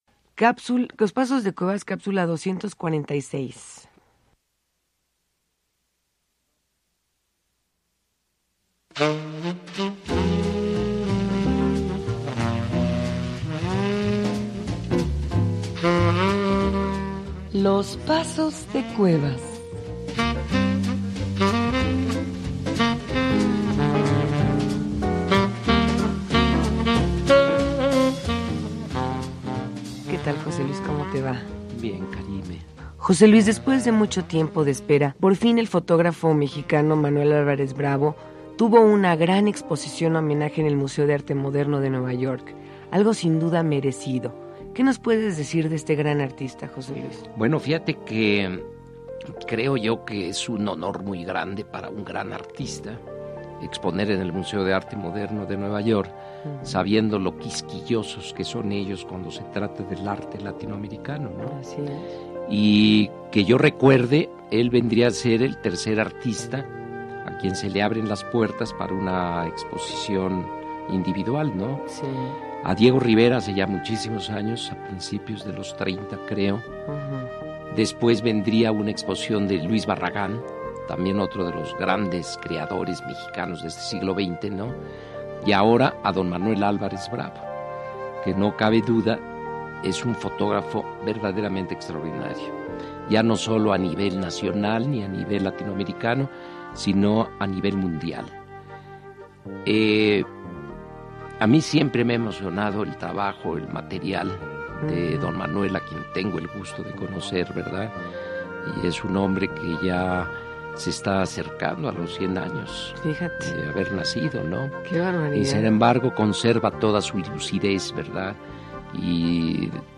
Escucha a José Luis Cuevas en su programa “Los pasos de Cuevas”, transmitido en 2001, en el que presenta un breve comentario sobre Manuel Álvarez Bravo y su obra.